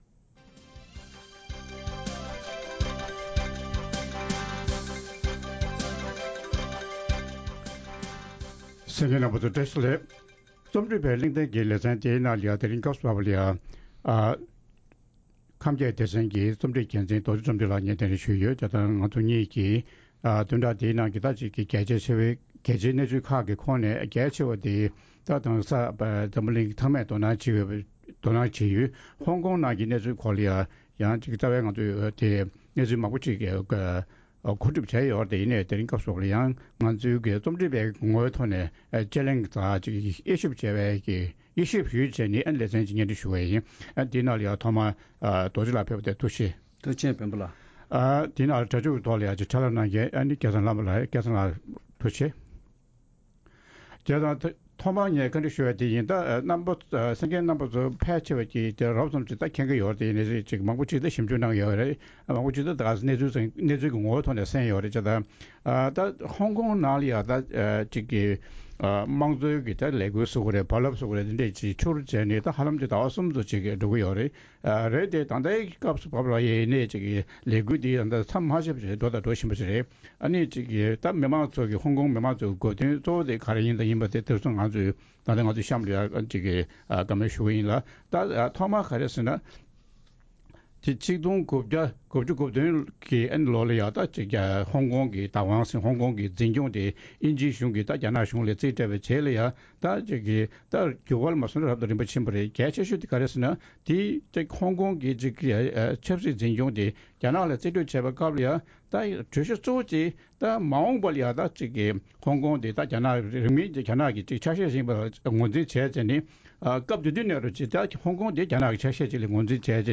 ཧོང་ཀོང་ནང་འབྱུང་བཞིན་པའི་ངོ་རྒོལ་དང་འབྲེལ་བའི་སྐོར། རྩོམ་སྒྲིག་འགན་འཛིན་རྣམ་པས་བགྲོ་གླེང་གནང་བར་གསན་རོགས་ཞུ།།